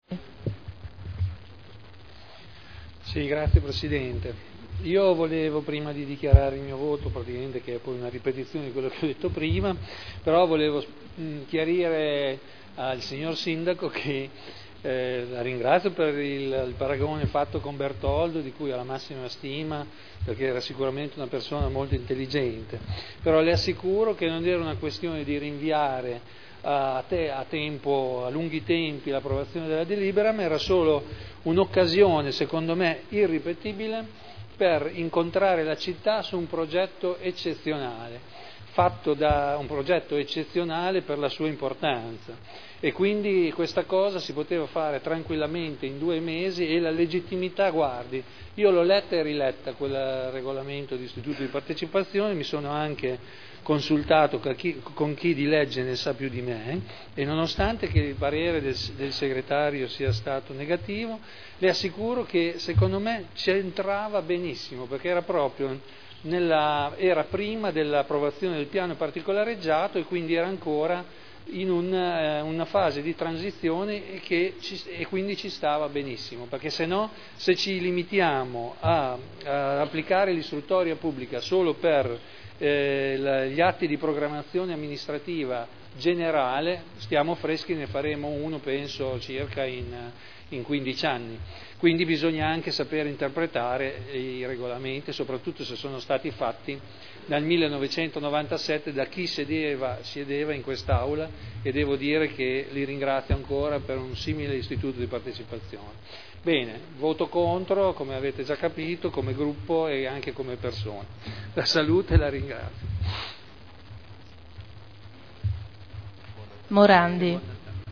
Vittorio Ballestrazzi — Sito Audio Consiglio Comunale
Seduta del 21/12/2009. Dichiarazione di voto.